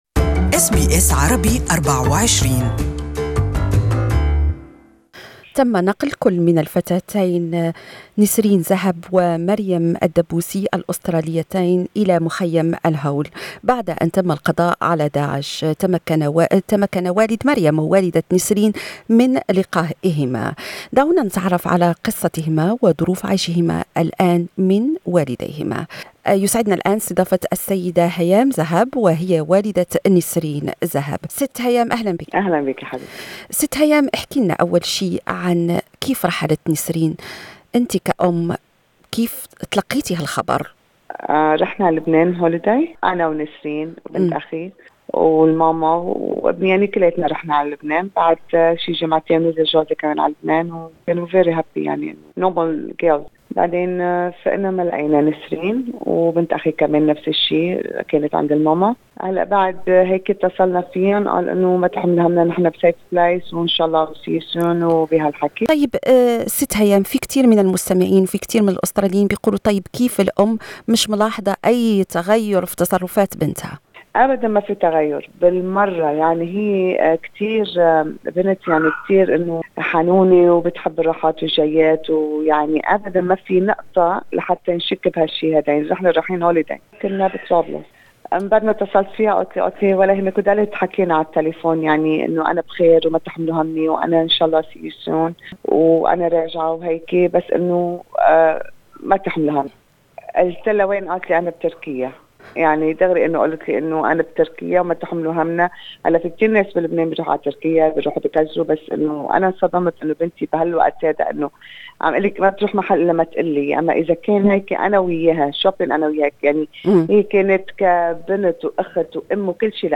أم أسترالية تحكي رحلة ابنتها من سيدني إلى مخيم زوجات داعش في سوريا